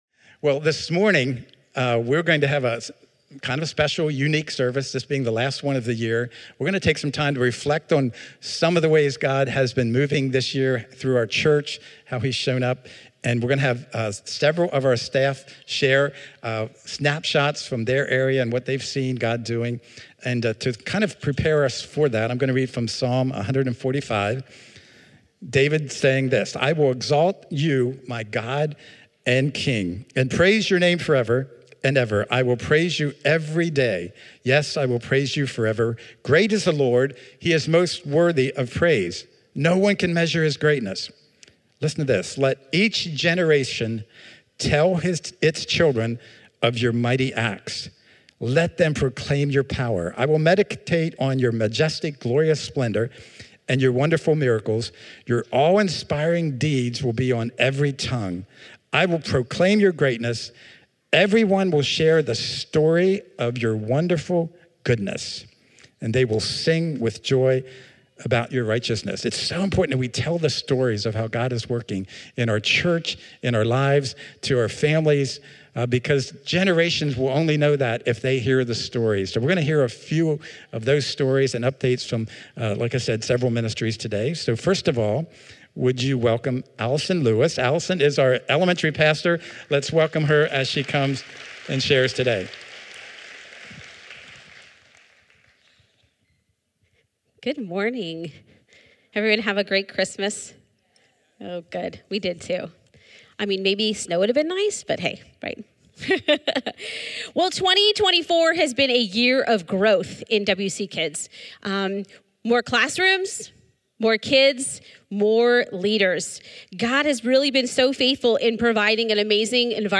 Celebrate what God did at Worship Center in 2024 — specifically in Kids Ministry, Small Groups, and Water Baptisms. Hear from a leader representing each of these areas who will share highlights, testimonies, and a prayer for 2025.